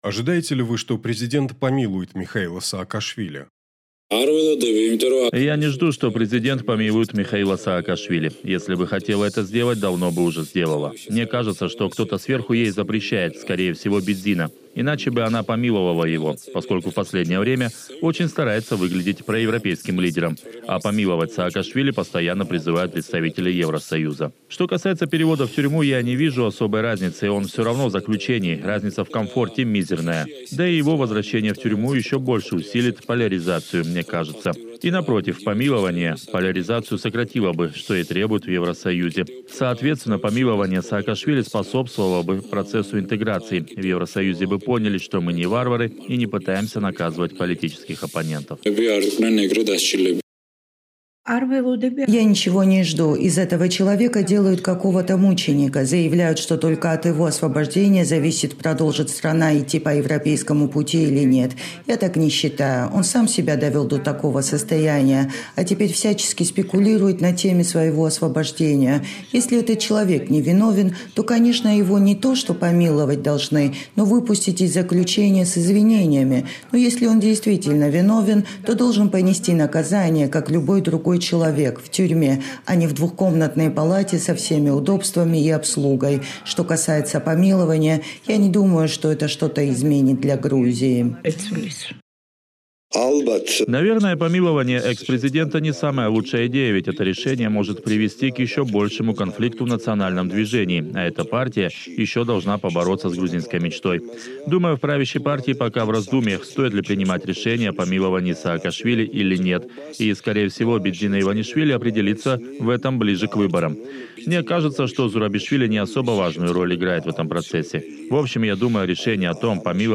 Часть оппозиции продолжает призывать президента Грузии к помилованию экс-главы государства Михаила Саакашвили, другая часть считает, что его пора вернуть в тюрьму. Своим мнением на этот счет с «Эхом Кавказа» поделились и пользователи социальных сетей.